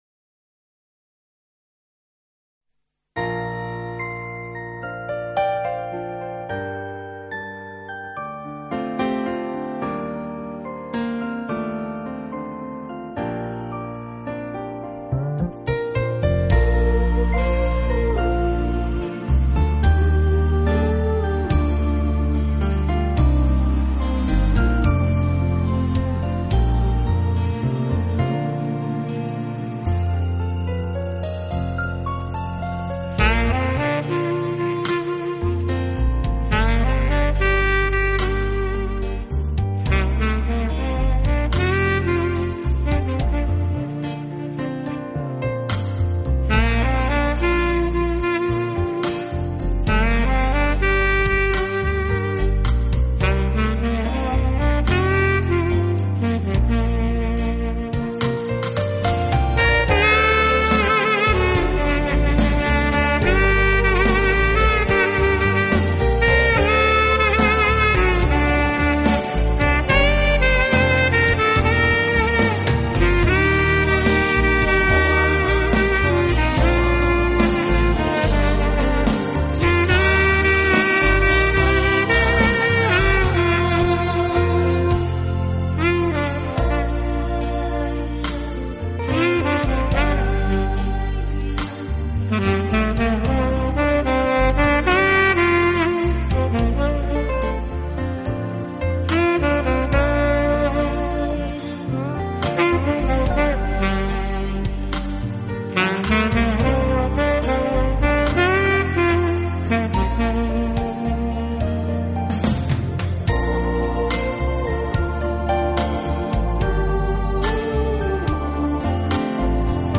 一杯咖啡伴随一首萨克斯音乐，让我们一起度过这最浪漫的时刻……